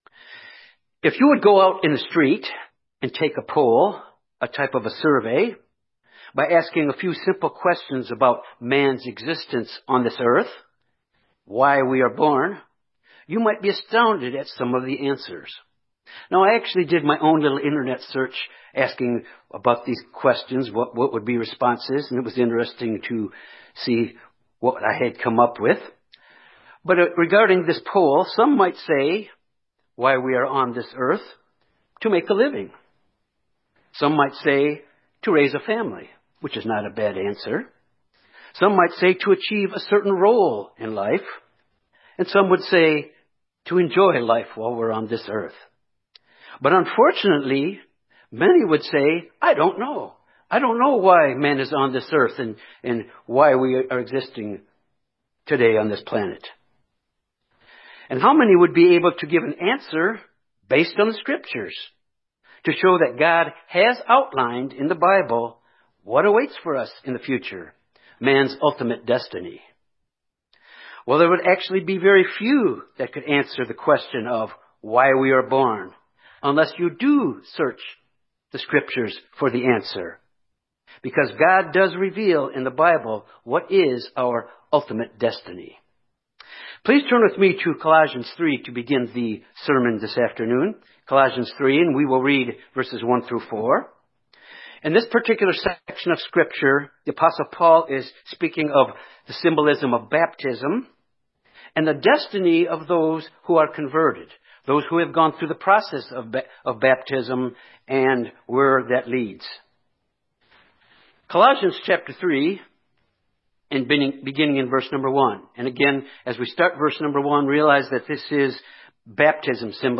This sermon examines the potential we have to become a part of the family of God and to share in the glory of God as is reflected in God’s Holy Days. As these Fall Holy Days approach, this message is to motivate us to be thinking about these Holy Days.